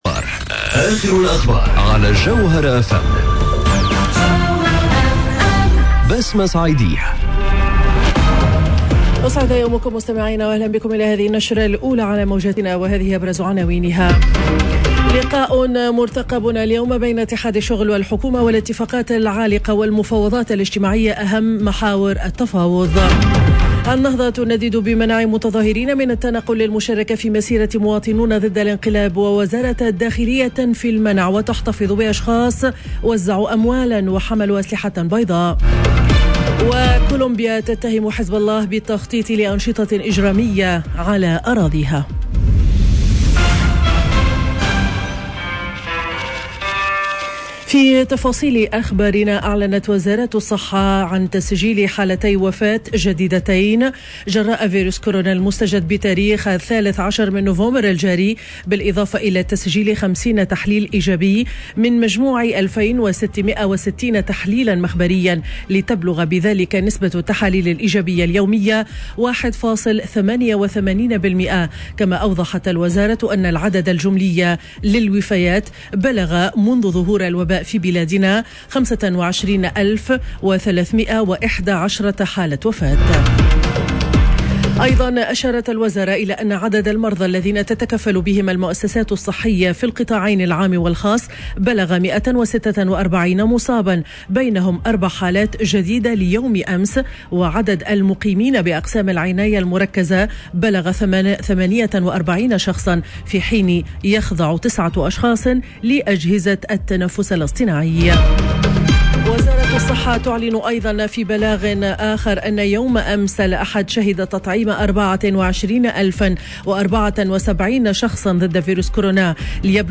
نشرة أخبار السابعة صباحا ليوم الإثنين 15 نوفمبر 2021